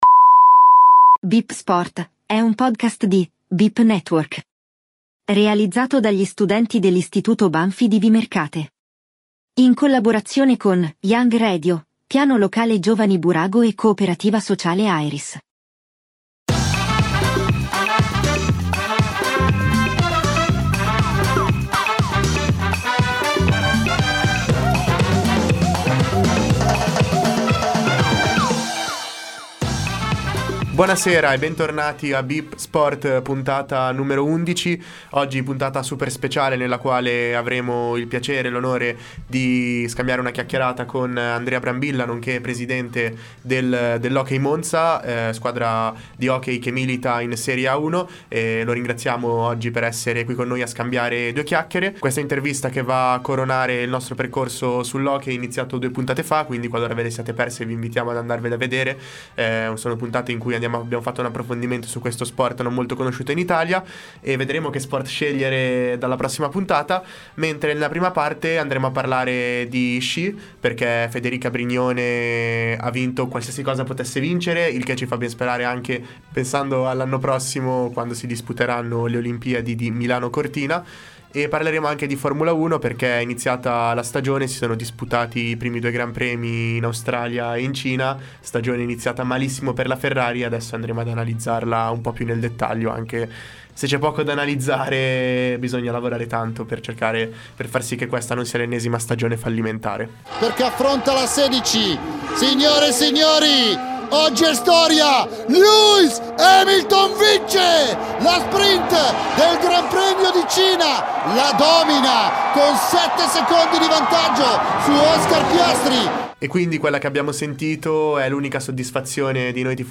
In questa puntata le notizie amare della Formula1 e la vittoria nello Sci di Federica Brignone. Intervista